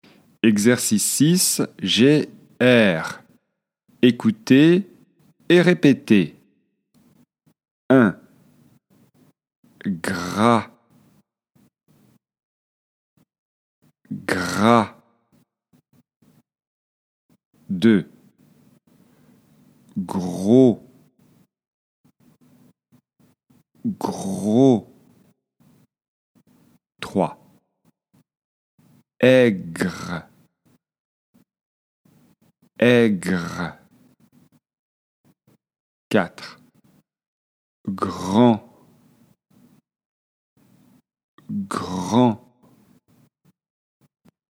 Prononciation française : consonnes suivies de R
📌 Écoutez et répétez :